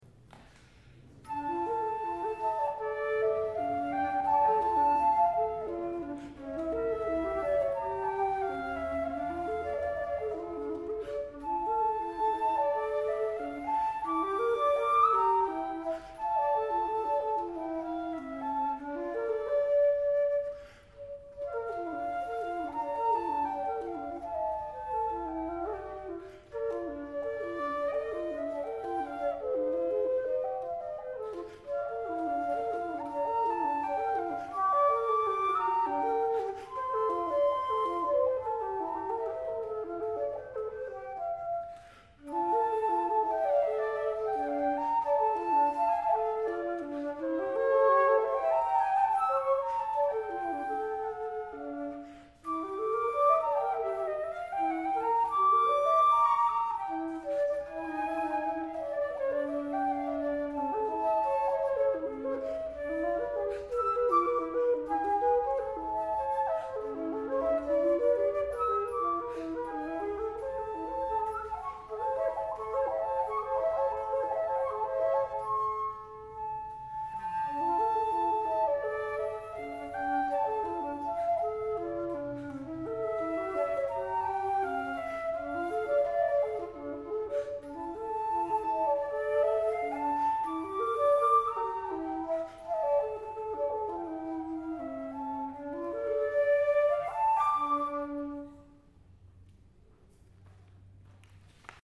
19th century flutes
1857 J. B. Streicher Grand Piano
This konzert took place June 12, 2004 in Berkeley, CA. A few musical excerpts, recorded LIVE at the konzert, are available here.
The flutes used are the fifth and sixth shown on the instruments page.
The flutes used by Ensemble KrazyKat are antique instruments made in Germany, Austria, and Russia — wooden, multi-keyed, conical-bore flutes from both the early and late nineteenth century.
(Listeners will hear a difference, however, between the early and late 19th century instruments, the latter being somewhat louder and sometimes a bit warmer.)
While it looks almost as large as a modern grand piano, its tone is more transparent and it damps quickly because of the Viennese action.